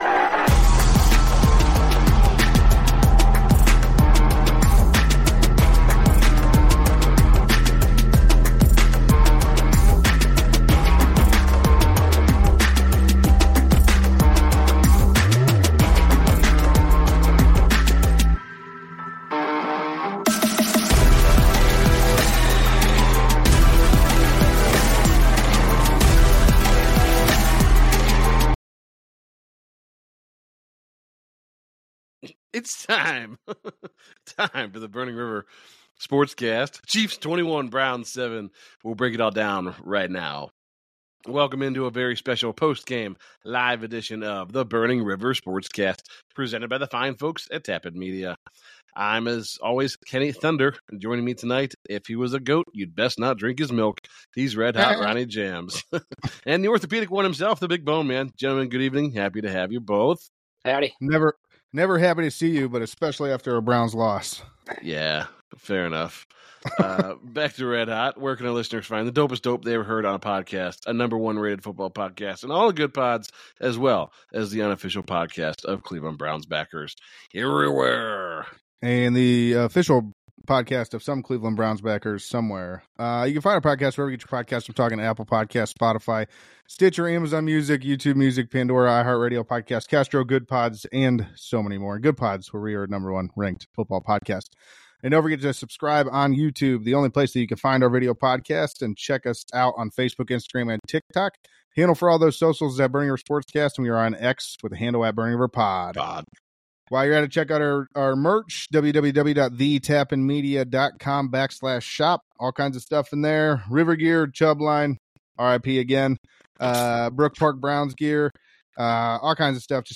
It's time! Time for Burning River Sportscast presented by Tap In Media to go live again!